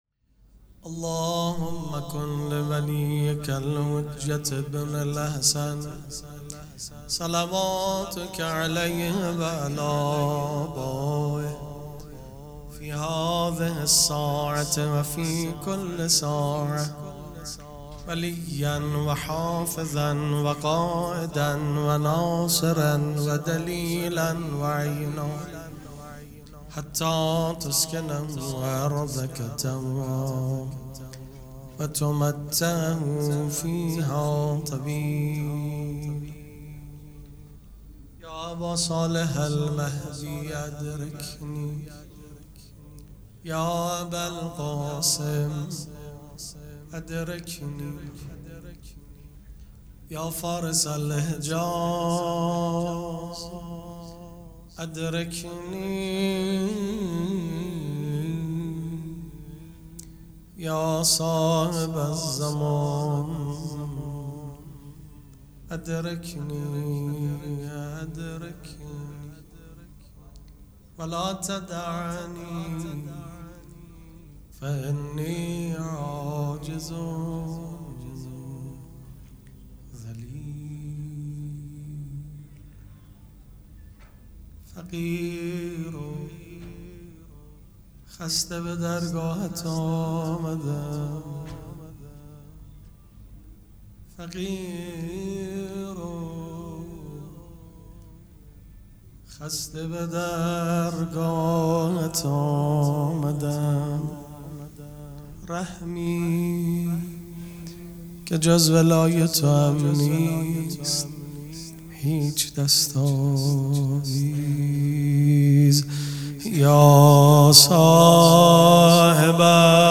ذکر توسل
مراسم عزاداری شهادت امام جواد علیه‌السّلام